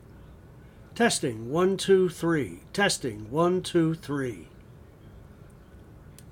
I am not happy with the way my voice sounds as it sounds to tenor in pitch.